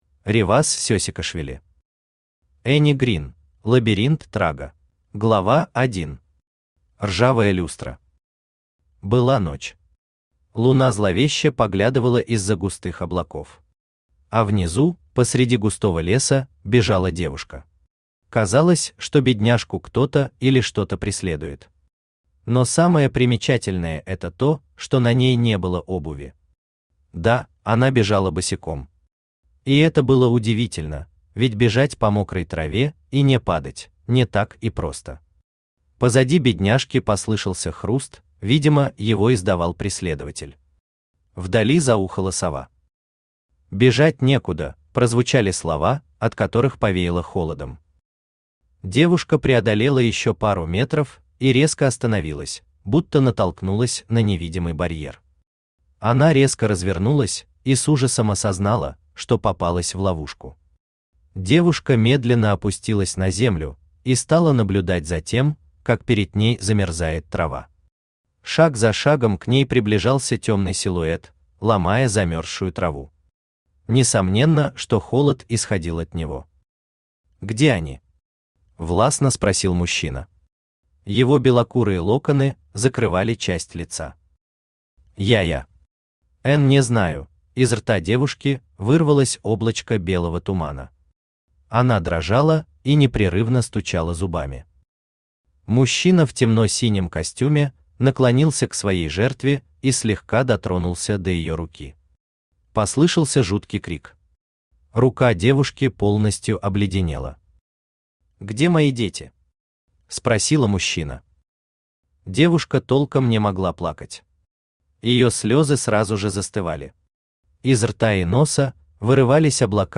Аудиокнига Энни Грин: Лабиринт Трага | Библиотека аудиокниг
Aудиокнига Энни Грин: Лабиринт Трага Автор Реваз Гурамович Сесикашвили Читает аудиокнигу Авточтец ЛитРес.